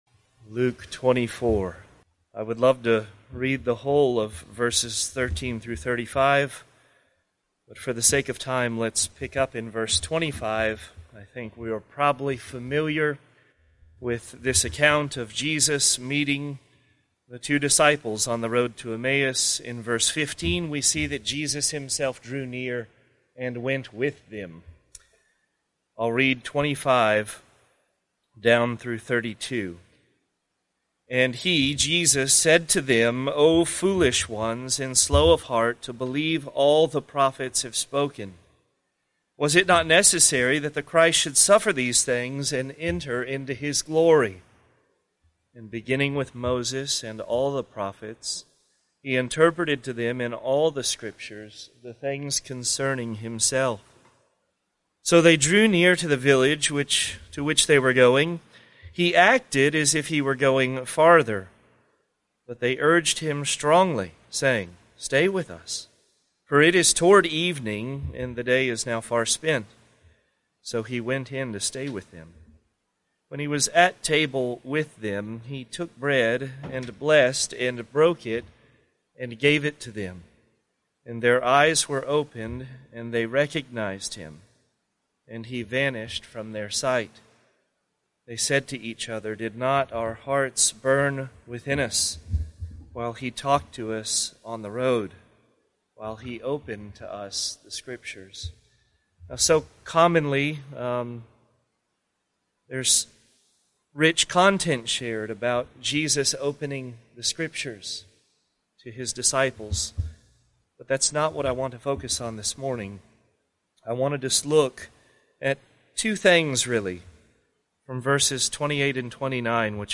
Prayer Meeting Exhortation | 7:41 | Jesus Christ wants communion and fellowship with His people.